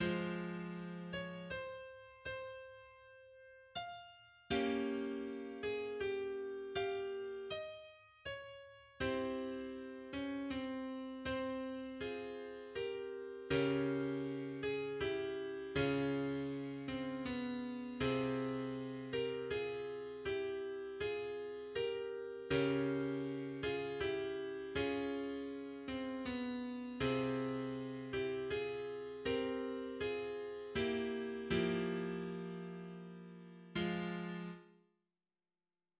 Genre Sonate pour piano
• Adagio, en fa mineur, à
Introduction de l'Adagio: